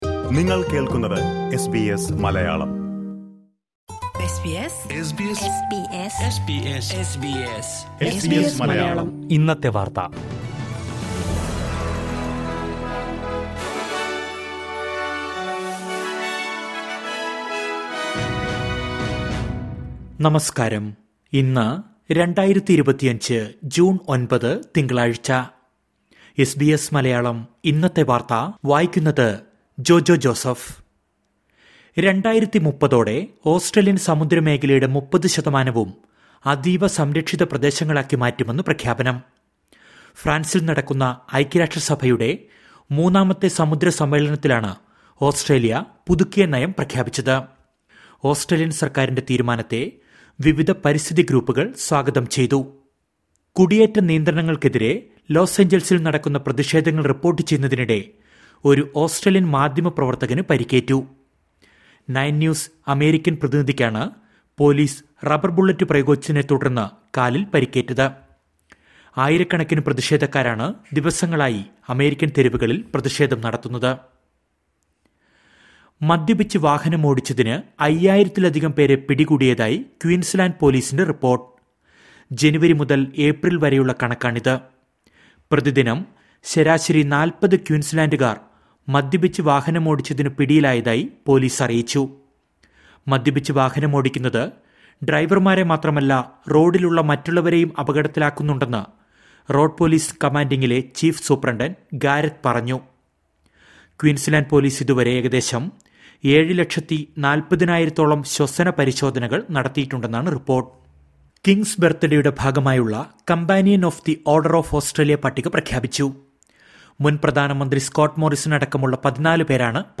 2025 ജൂൺ ഒൻപതിലെ ഓസ്‌ട്രേലിയയിലെ ഏറ്റവും പ്രധാന വാര്‍ത്തകള്‍ കേള്‍ക്കാം...